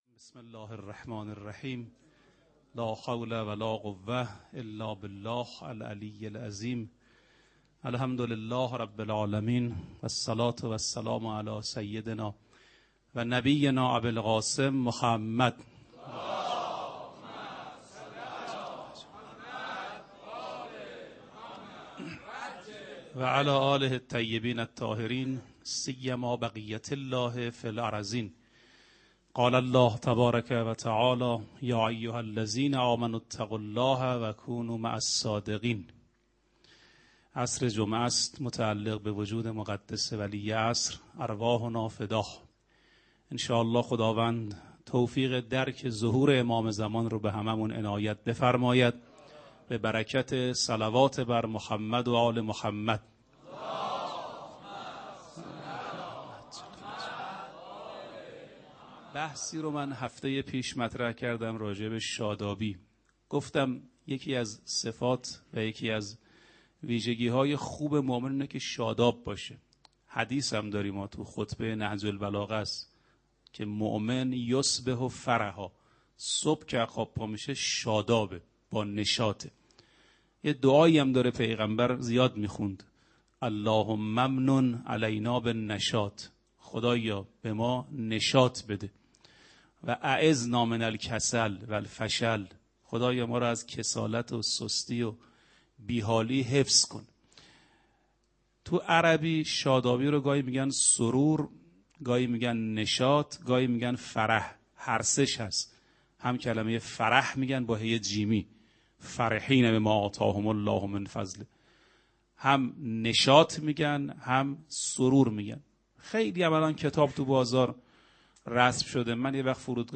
آرشیو ماه مبارک رمضان - سخنرانی - بخش سی ام